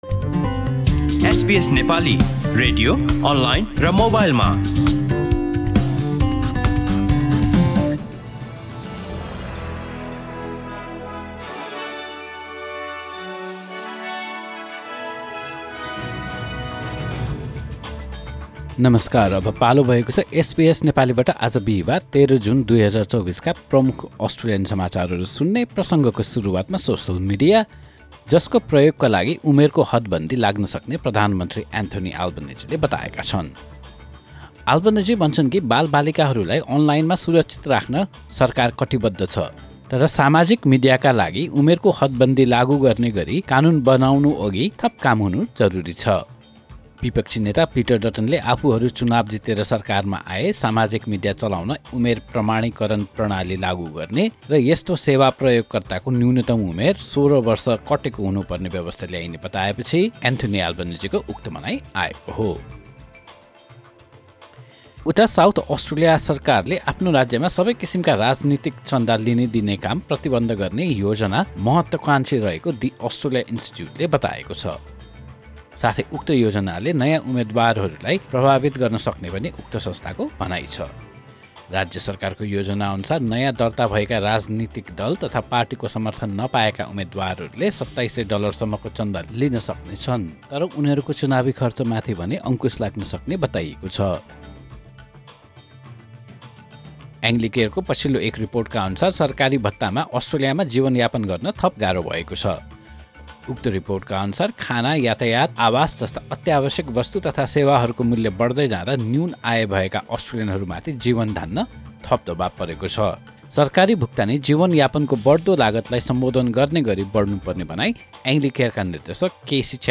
SBS Nepali Australian News Headlines: Thursday, 13 June 2024